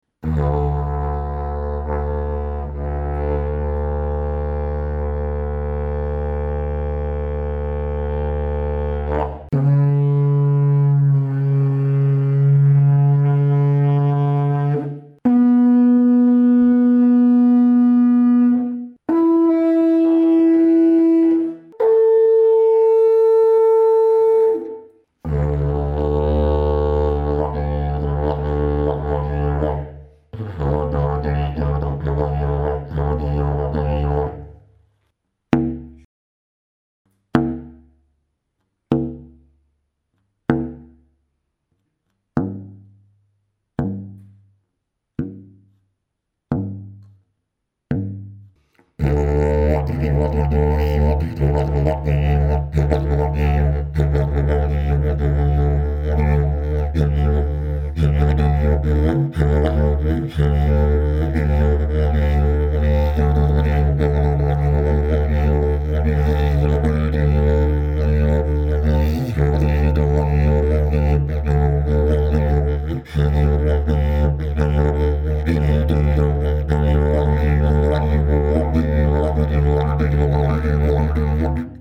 Dg558 ist ein Didgeridoo meines Modells 046 gestimmt in D2, mit dem Overblow unter der Oktave C#3+50. Der sehr stabile und leicht zu spielende Grundton hat sehr effektiven Resonanzwiderstand, der erste Overblow liegt etwas tiefer als C#+50 als die Oktave. Die Form erzeugt einen scharfen aber nicht so stark dröhnenden Grundton, der sich gut mit Stimmeffekten modulieren lässt und dadurch auch Mikrophone nicht leicht übersteuert.
Fundamental note, draw ranges and resonances of the overblows at 24� C: D2 � 5 (Bn1 to D2 � 40) // C# � 50 (+30, D3 � 30) / Bn3 � 20 / E4 � 5 __/ A4 � 20 Dg558 Technical sound sample 01